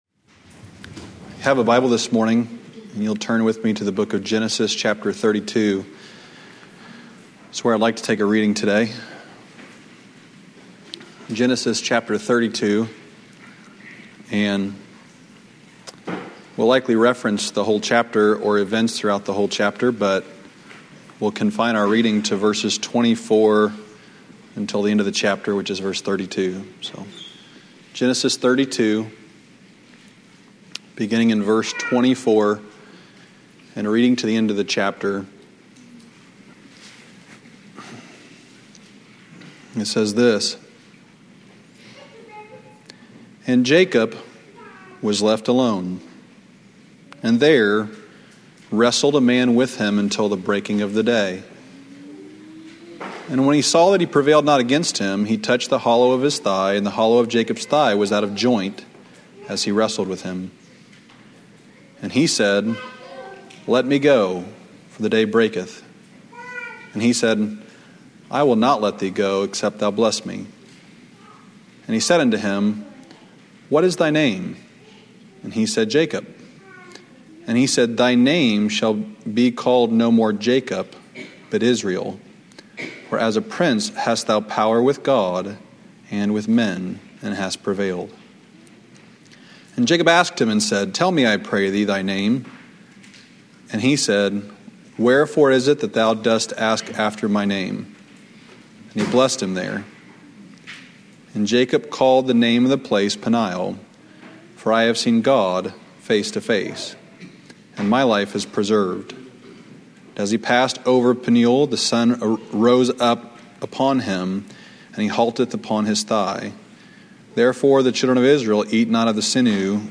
"Drive and Go Forward" 2 Kings 4:22-24 Sunday evening revival sermon from July 21, 2024 at Old Union Missionary Baptist Church in Bowling Green, Kentucky.